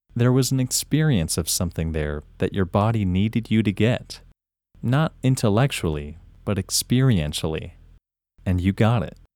IN – First Way – English Male 30
IN-1-English-Male-30-1.mp3